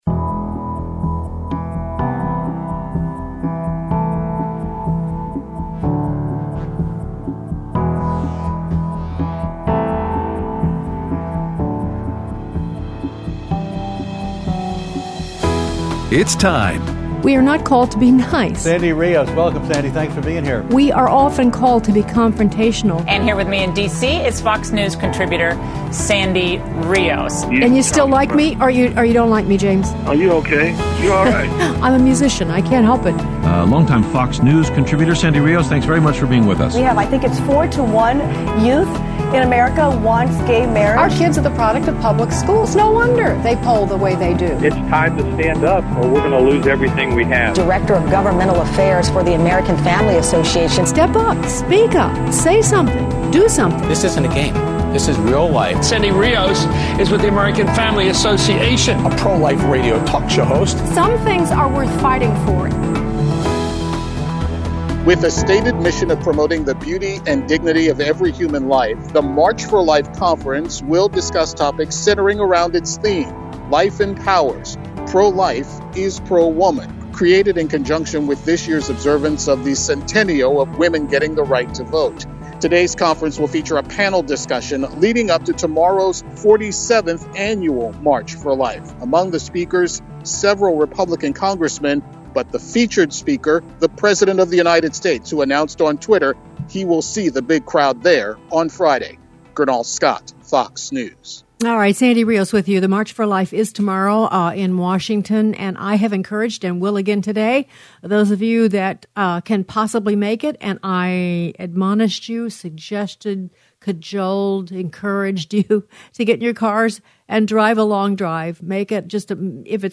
Aired Thursday 1/23/20 on AFR 7:05AM - 8:00AM CST